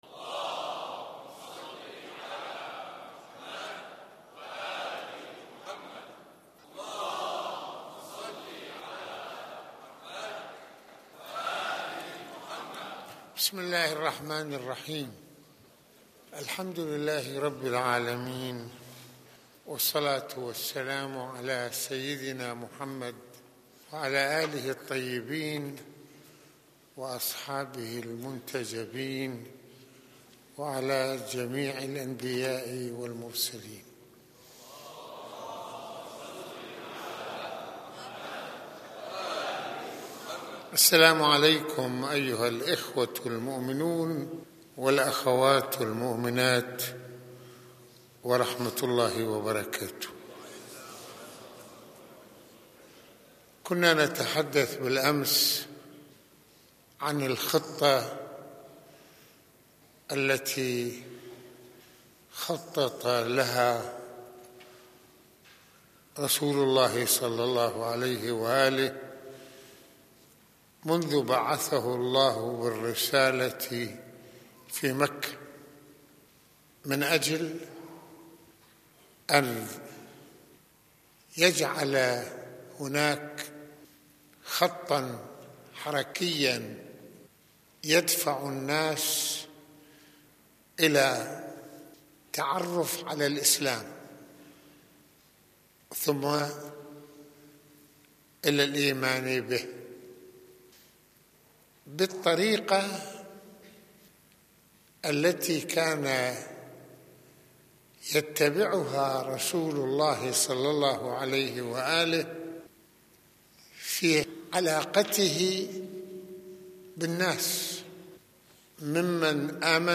المناسبة : عاشوراء المكان : مسجد الإمامين الحسنين (ع)